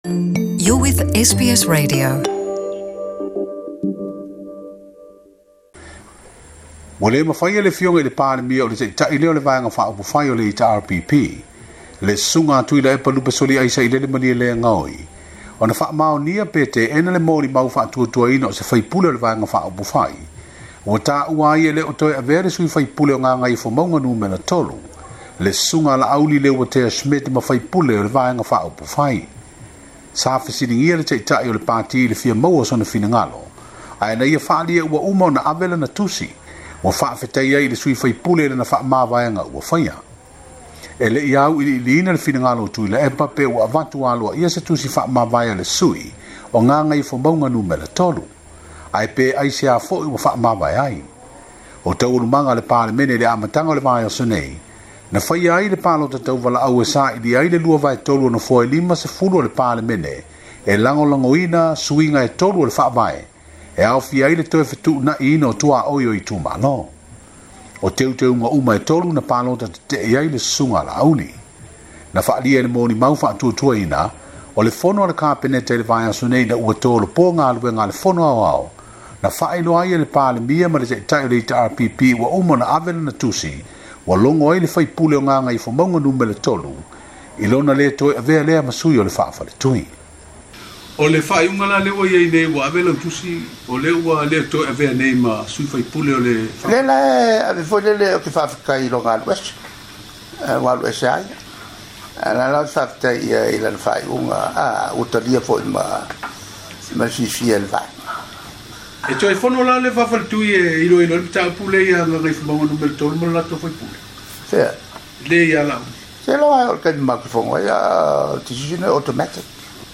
Faafofoga mai i le saunoaga a le palemia o Samoa, Tuilaepa Lupesoliai Sailele Malielegaoi, i le mafua'aga ua le toe avea ai le sui o Gagaemauga Numera 3, Laauli Polataivao Schmidt, ma sui o le vaega 'upufai le HRPP.